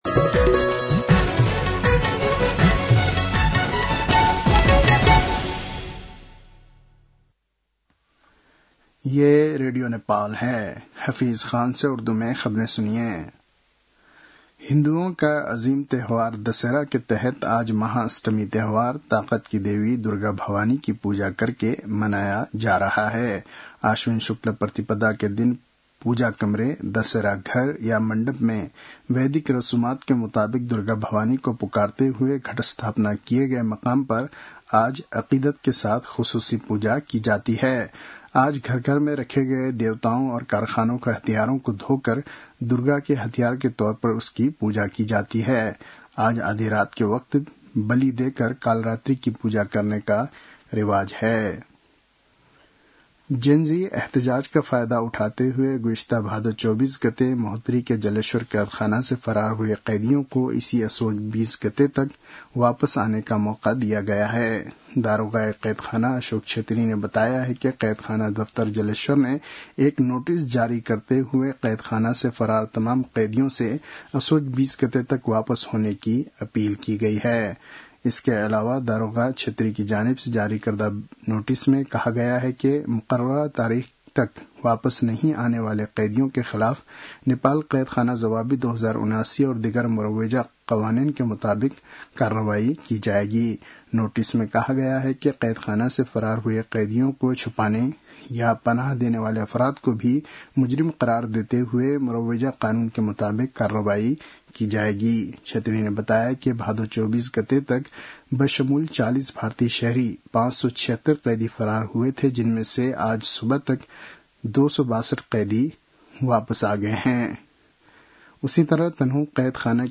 उर्दु भाषामा समाचार : १४ असोज , २०८२